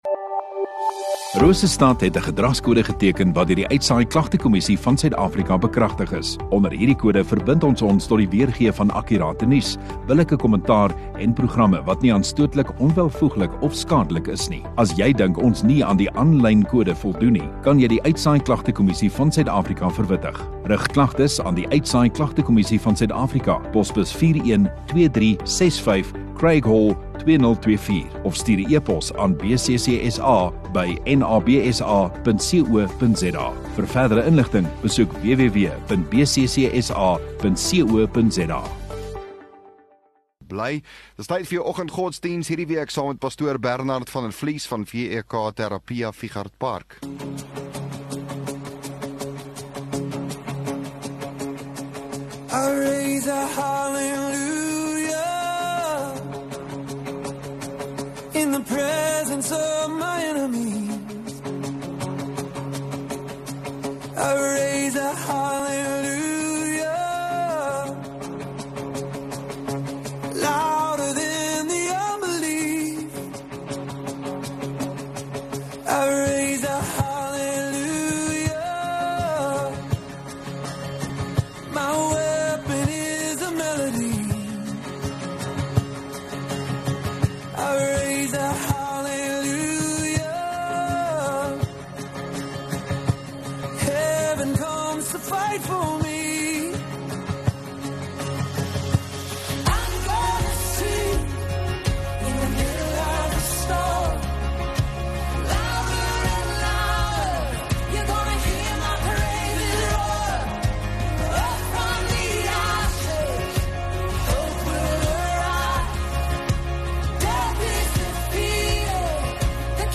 24 Jul Donderdag Oggenddiens